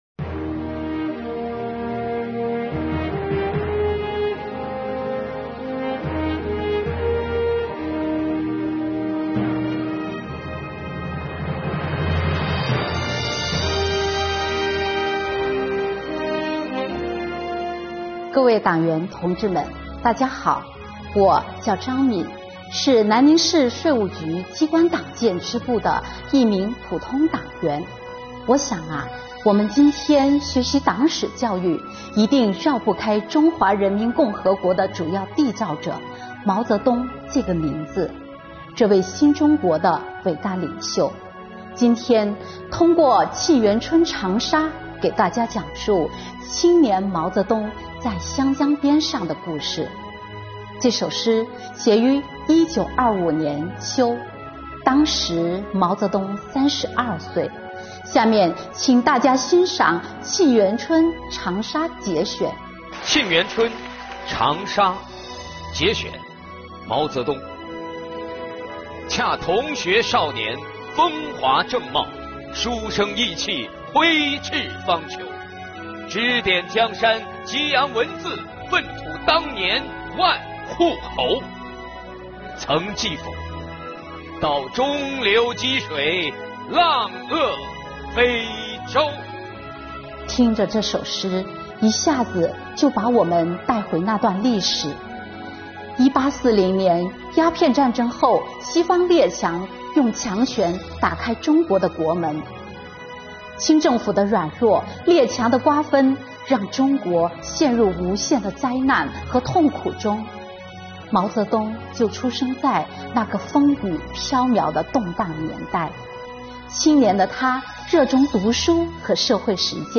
今天发布南宁市税务局机关党委青年说党史之《湘江边上的青年毛泽东》。